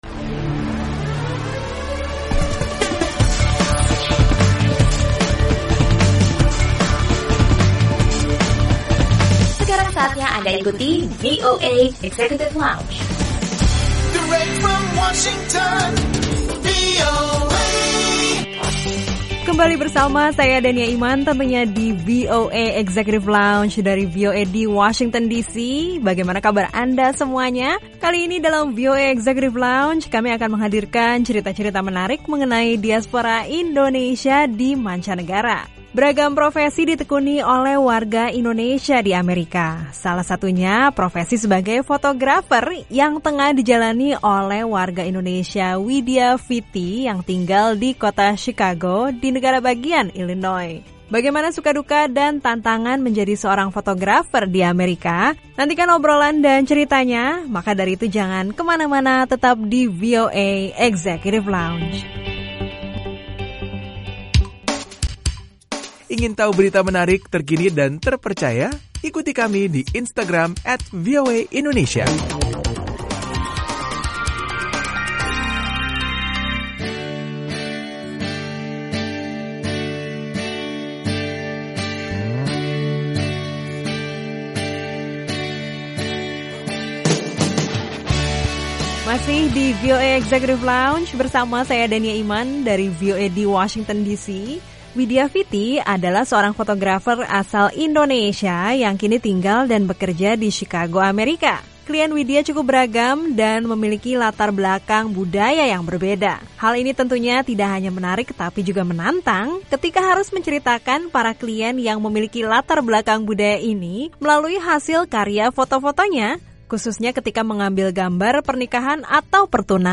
Simak obrolan bersama warga Indonesia